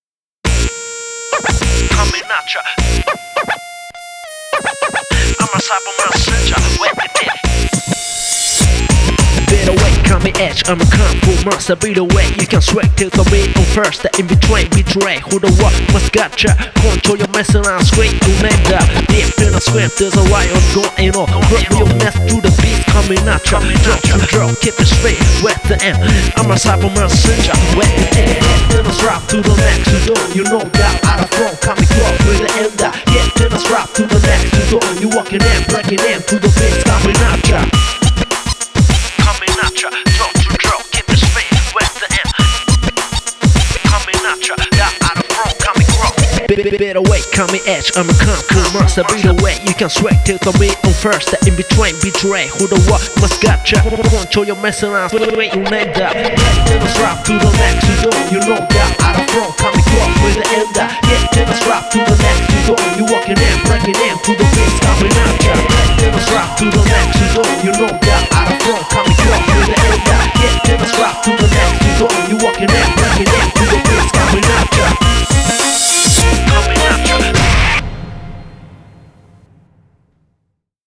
BPM103
Audio QualityLine Out